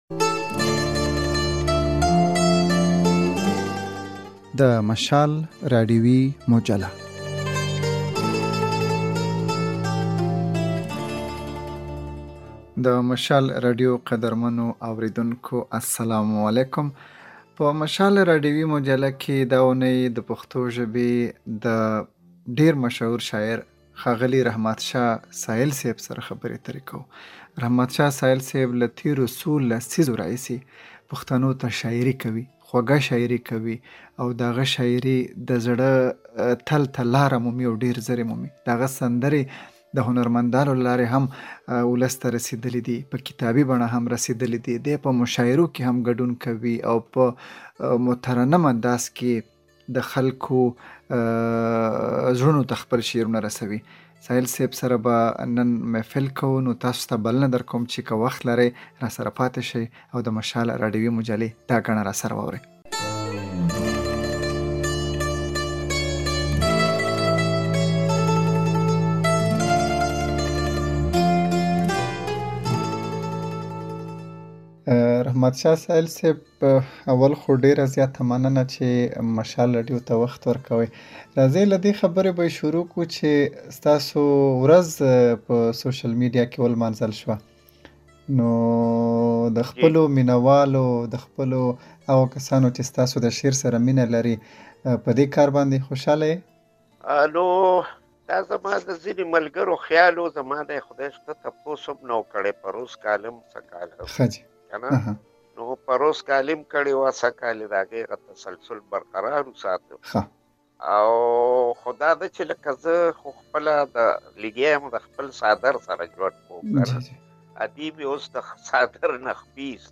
دا اوونۍ په مشال راډیويي مجله کې له وتلي شاعر رحمت شاه سایل سره د هغه پر ژوند او فن غږېدلي یو.
موږ هم په همدې پلمه له ښاغلي رحمت شاه سایل سره خبرې کړي او د نوموړي د ژوند او کار پاڼې مو اړولې دي.